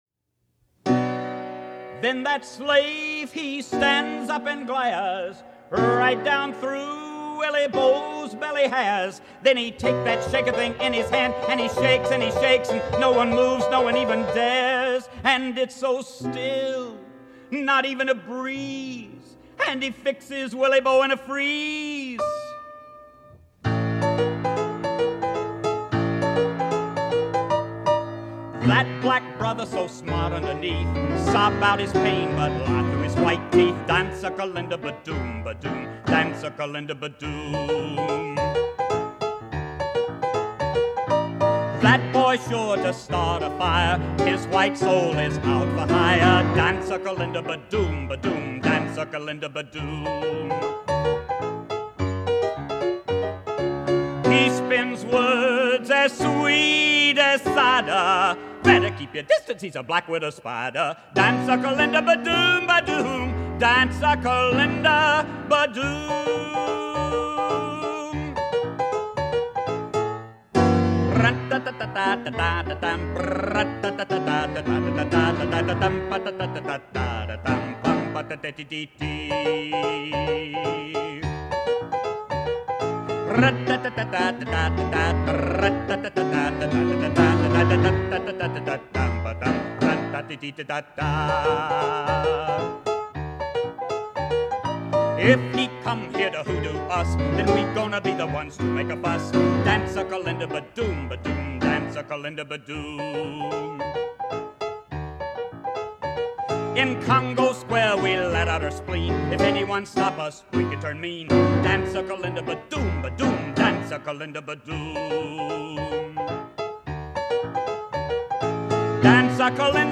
Accompanied on piano